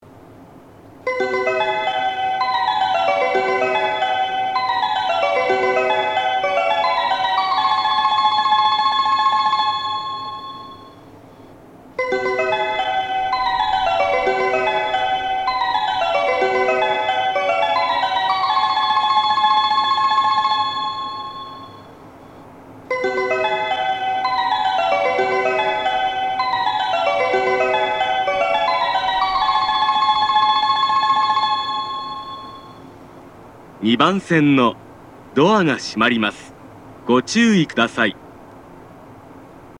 3コーラス
3コーラスです!